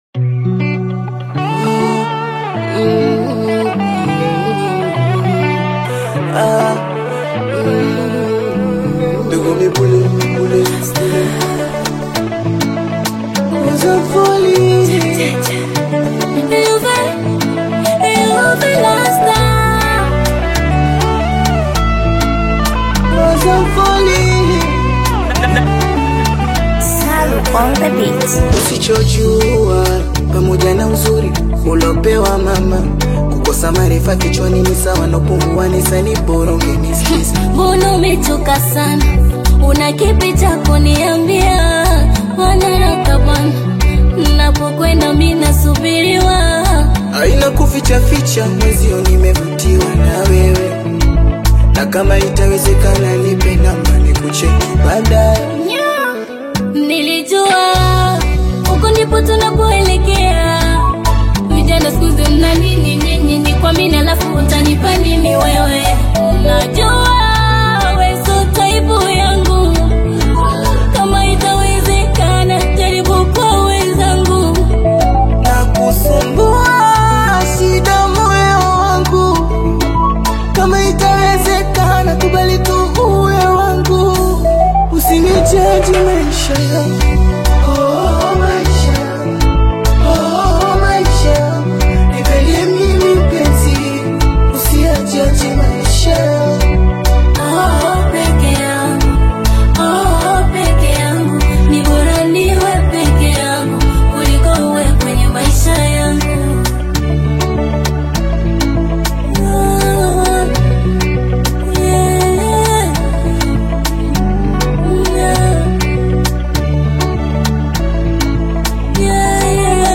a deeply atmospheric track